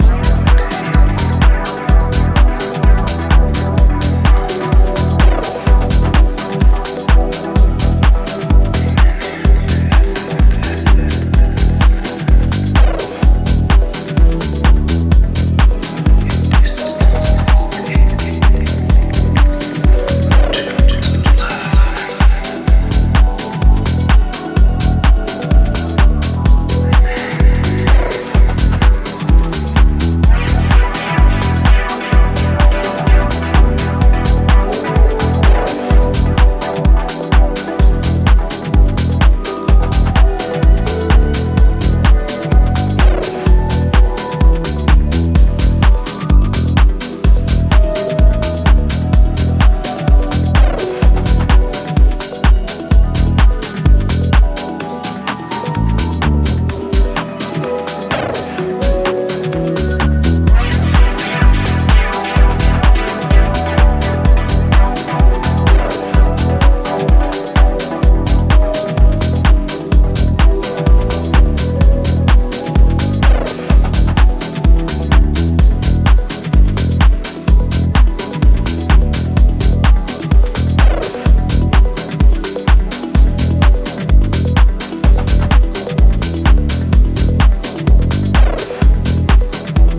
ジャンル(スタイル) DEEP HOUSE / HOUSE / TECH HOUSE